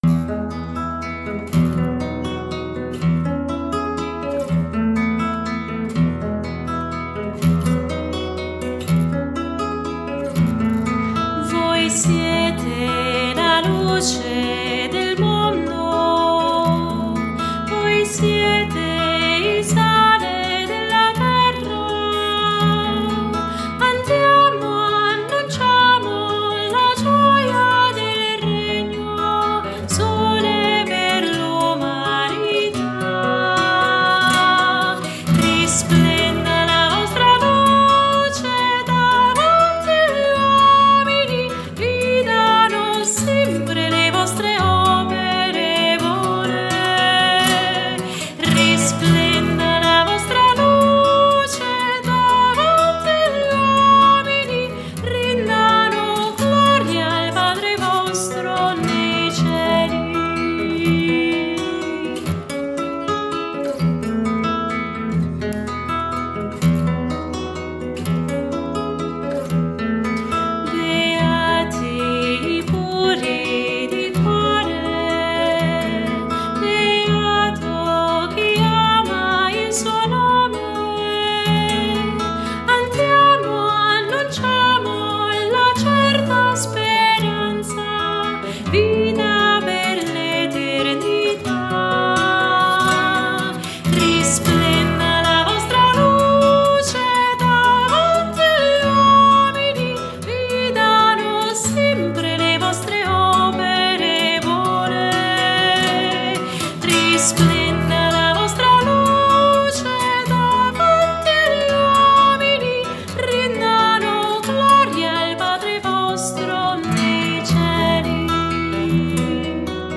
Canto dei candidati al sacerdozio 2012